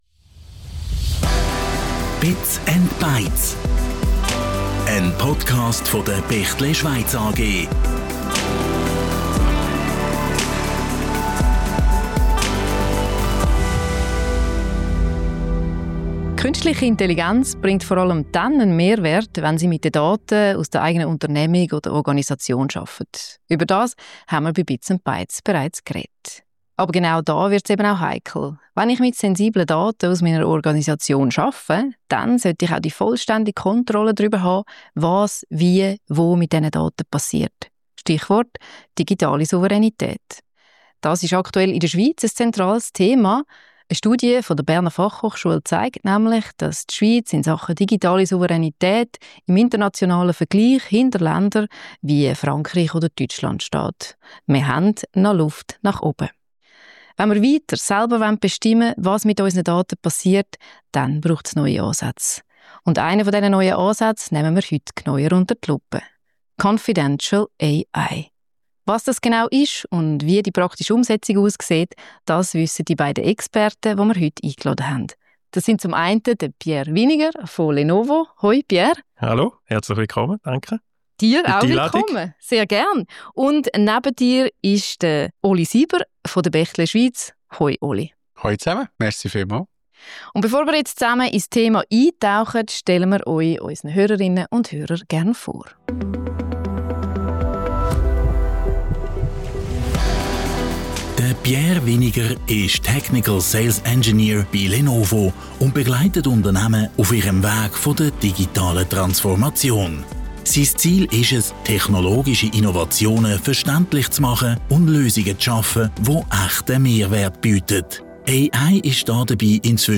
Die drei diskutieren praxisnah, wie Firmen und Organisationen digitale Souveränität (wieder)erlangen, welche Risiken durch Schatten-IT und unkontrollierte Cloud-Nutzung entstehen und wie man durch Schulungen und technische Ansätze wie Confidential Computing Vertrauen und Sicherheit zurückgewinnt. Außerdem geben sie konkrete Tipps, wie Unternehmen ihre ersten Schritte Richtung KI machen können – auch ohne tiefes technisches Vorwissen.